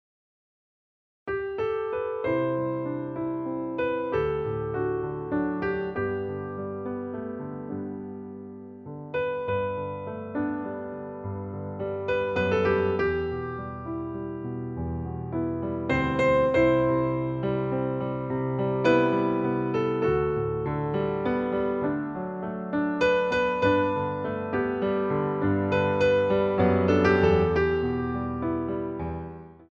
PLIÉS - 3/4